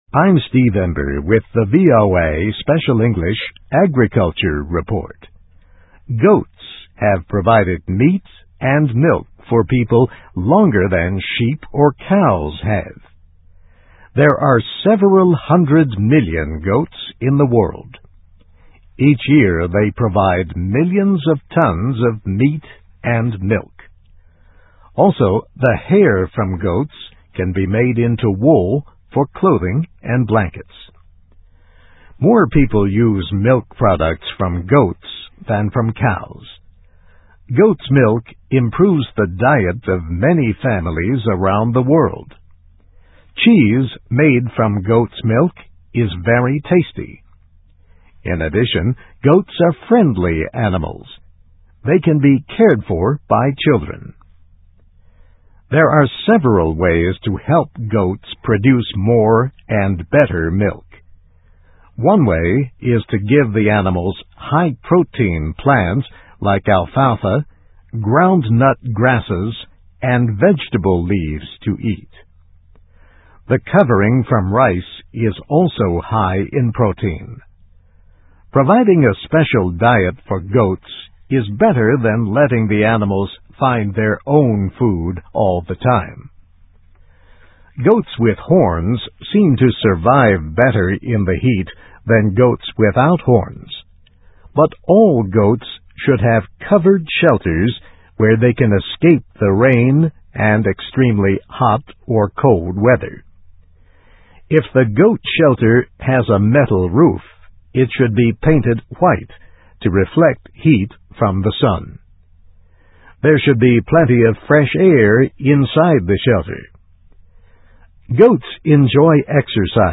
How: Goats: Friendly Animals That Can Be Cared for by Children (VOA Special English 2005-11-28)